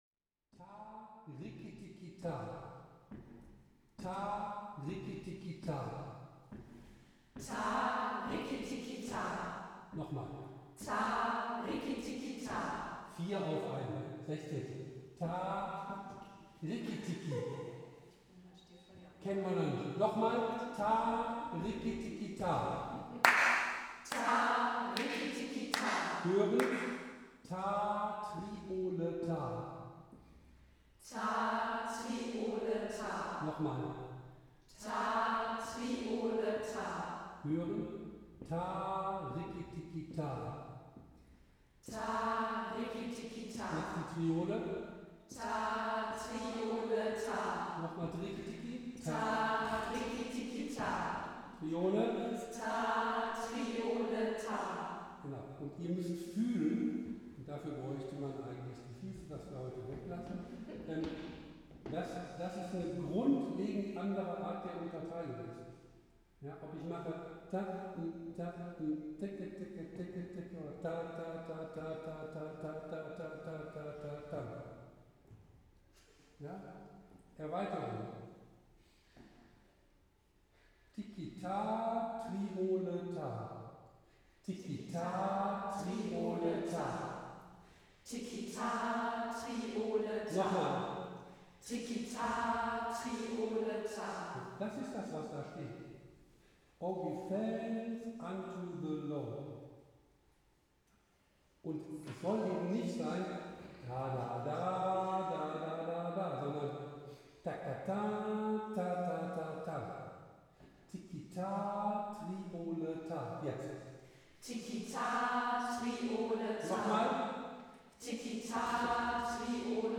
O give thanks | rhythmische Vorübung zum Anfang
rhythmische-Voruebung-zum-Anfang.mp3